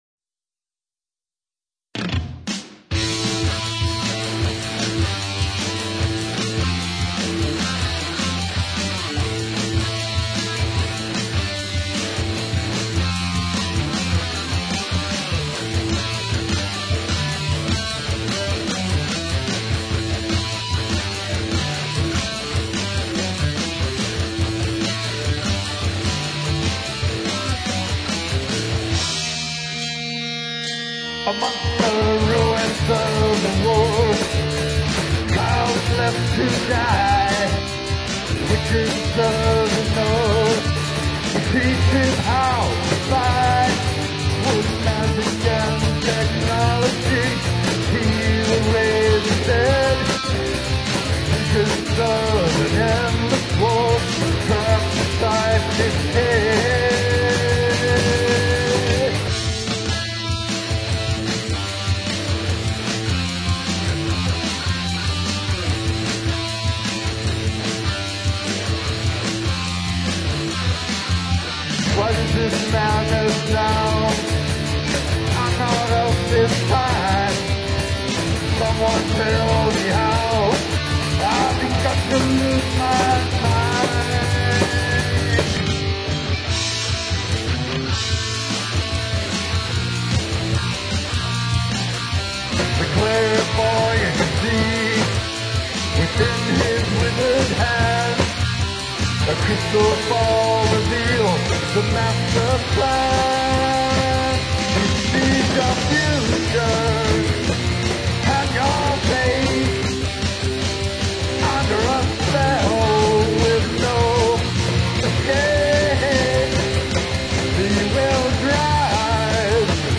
TEMPO 145-7
NO VOCALS